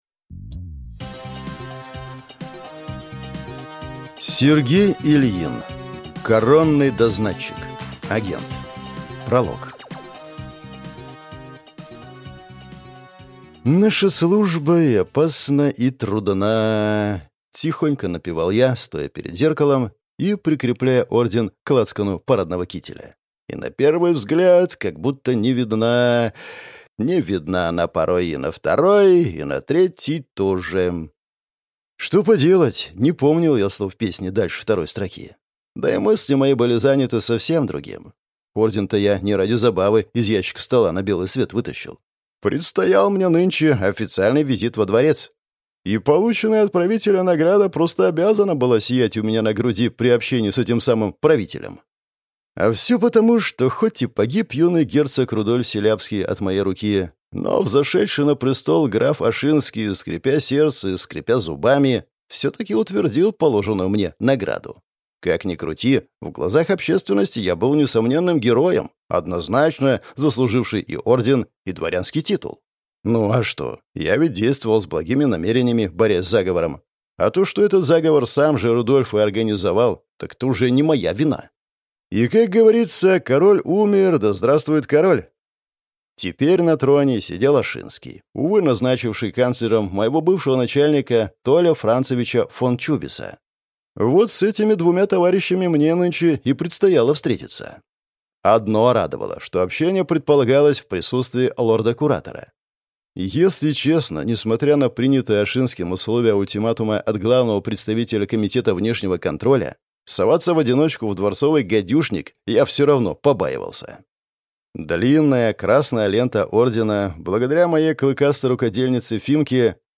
Аудиокнига Коронный дознатчик. Агент | Библиотека аудиокниг